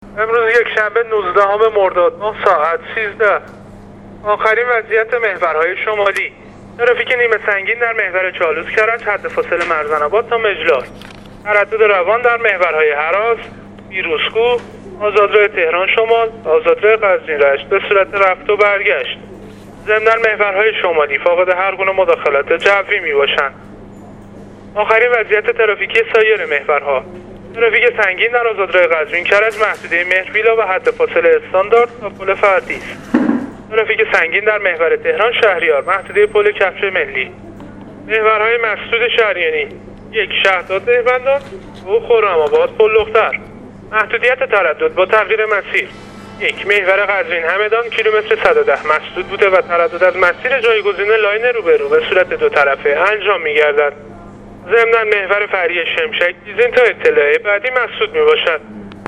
گزارش رادیواینترنتی از وضعیت ترافیکی جاده‌ها تا ساعت ۱۳ نوزدهم مرداد